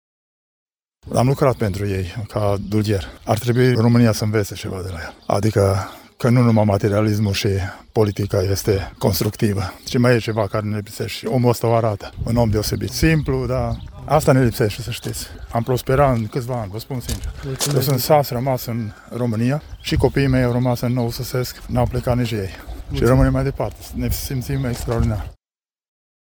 SAS-DULGHER-PENTRU-REGE.mp3